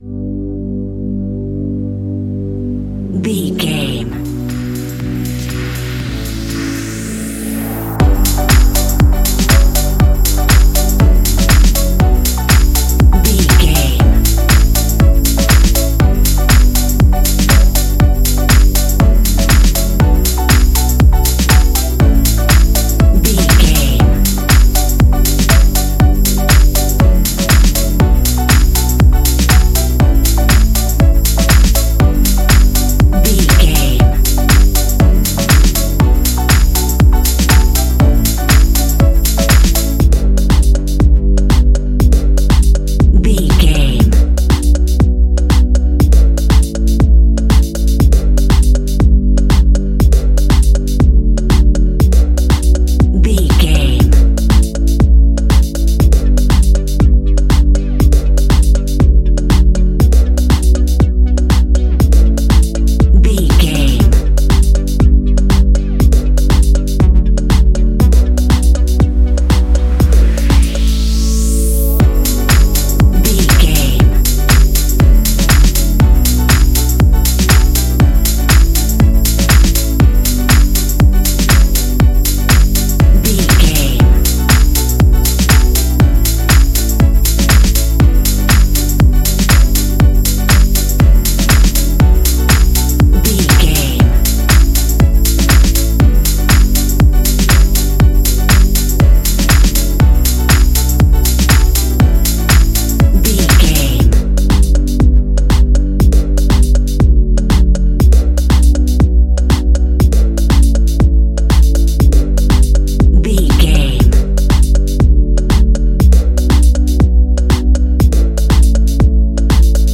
Ionian/Major
house
electro dance
electronic
synths
techno
trance
instrumentals